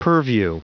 Prononciation du mot purview en anglais (fichier audio)
Prononciation du mot : purview